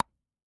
Звуки стука зубов
Звук смыкания верхней и нижней челюсти